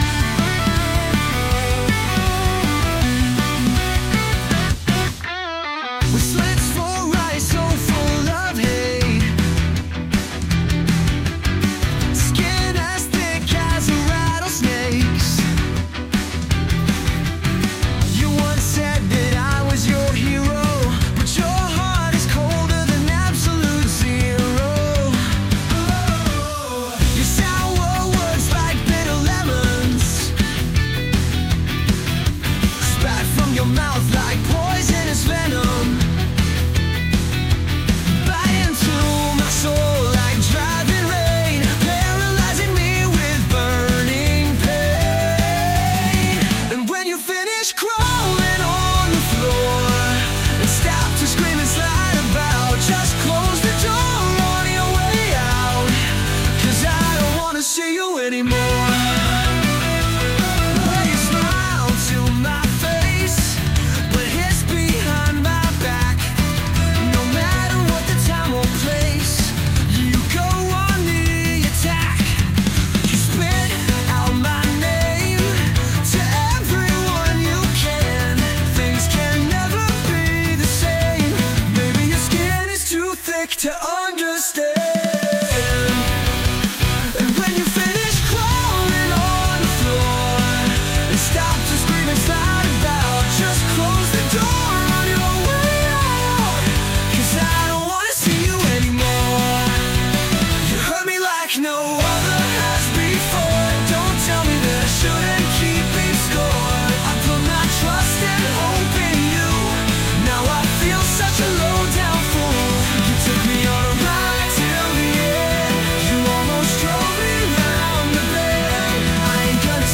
raw and emotionally charged song